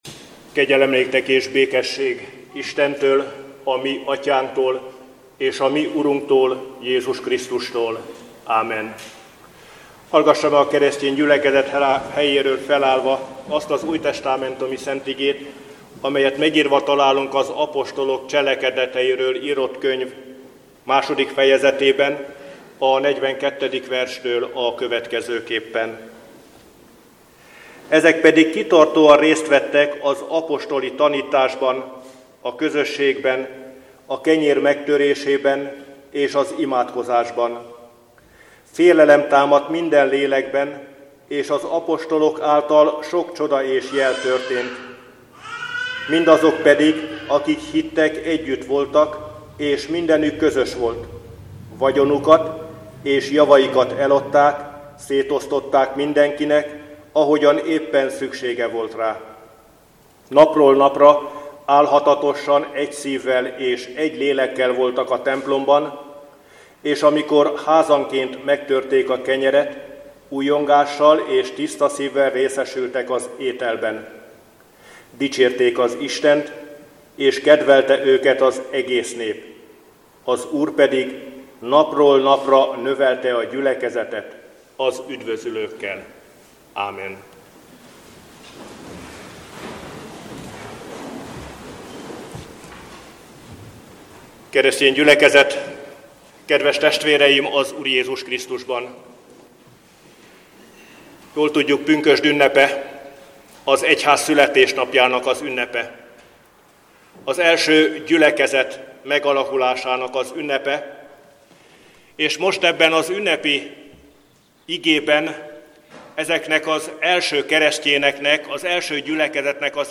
(Zak 4,6) - Az istentisztelet végén a gyülekezet kórusa énekel.